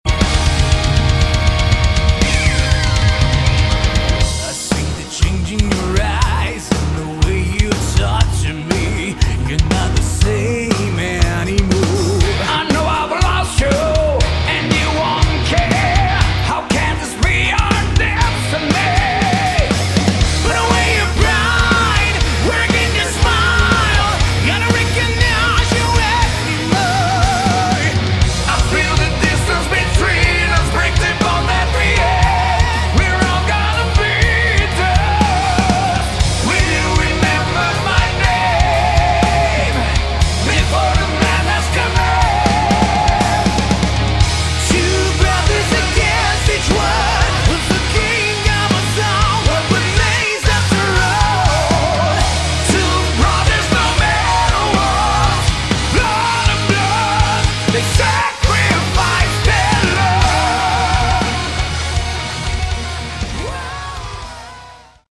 Category: Melodic Metal
vocals
bass, keyboards, backing vocals
guitars
drums